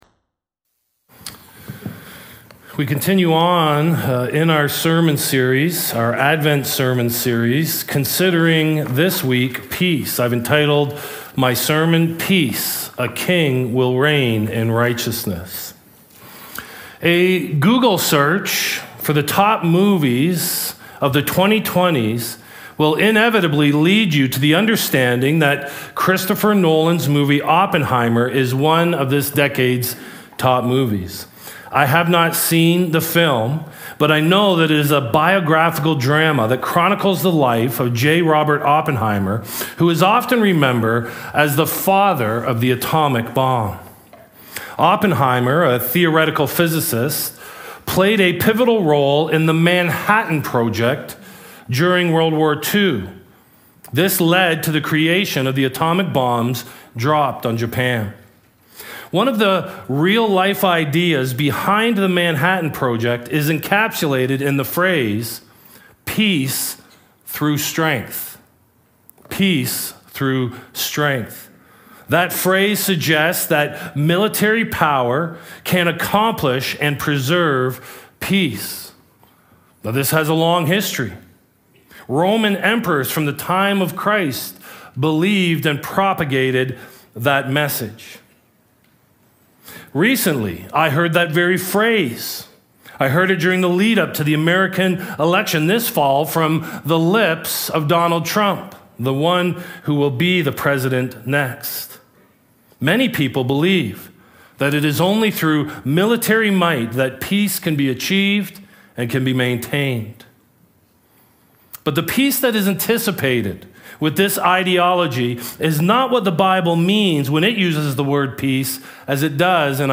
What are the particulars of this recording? Euro 2024: England 0 Slovenia 0 - post-match podcast from the Cologne stands